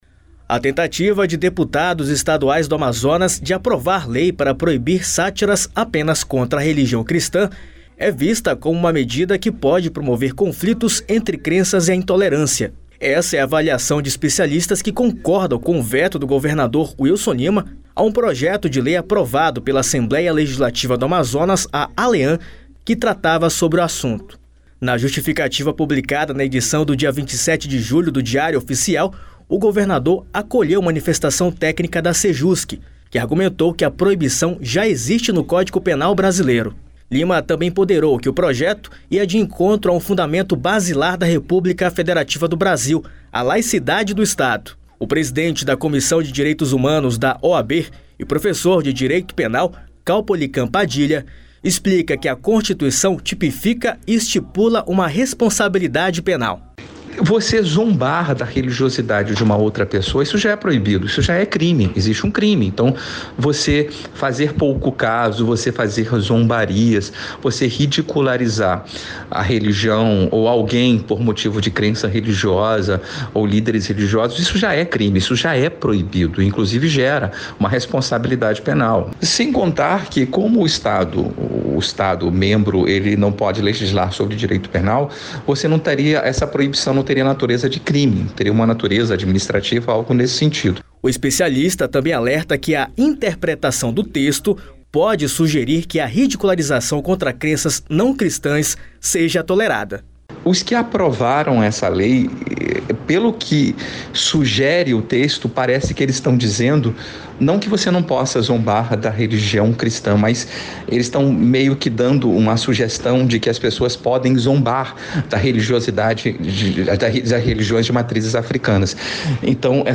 O líder do governo, Felipe Souza (Patriota) defendeu o projeto e disse desconhecer que outras religiões eram tão ridicularizadas quanto as cristãs.
Já a vice-líder Alessandra Campêlo (Podemos) alertou sobre a falta do PL contemplar outras religiões como o judaísmo, islamismo e cultos afro-brasileiros.
Reportagem